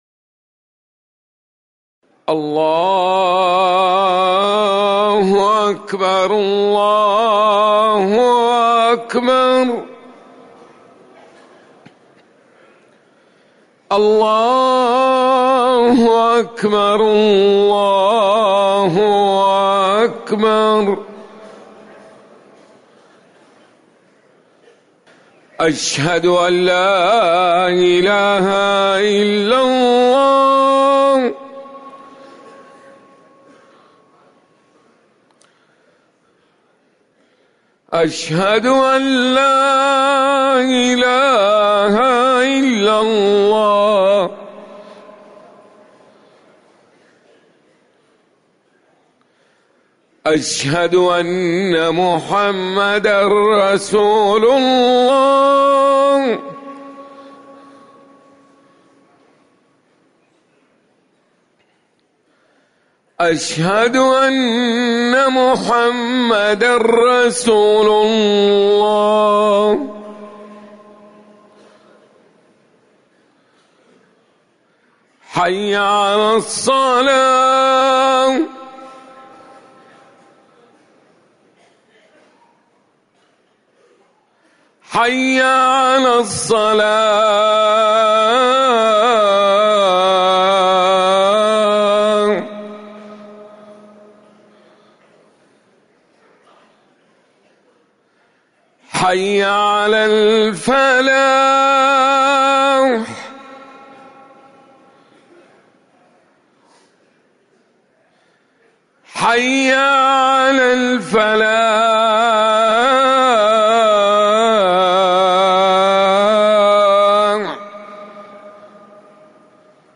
أذان الفجر الأول
تاريخ النشر ٣ محرم ١٤٤١ هـ المكان: المسجد النبوي الشيخ